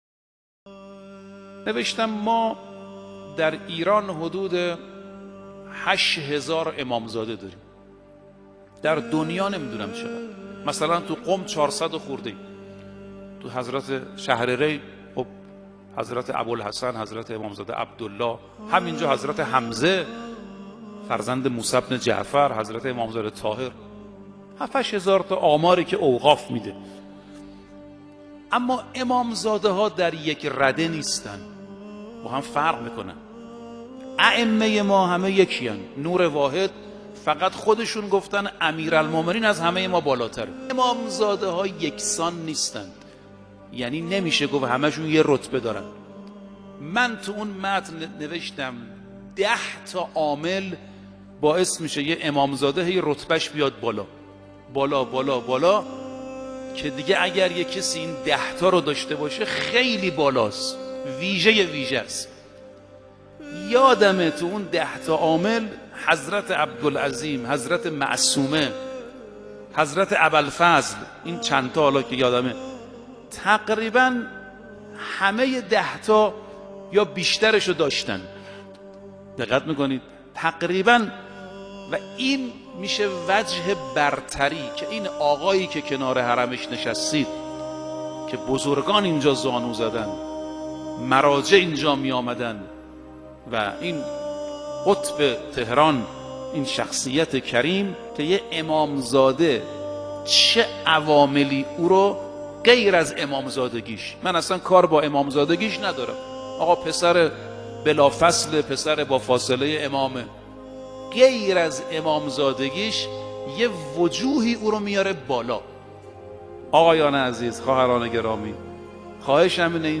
به مناسبت میلاد حضرت عبدالعظیم(ع)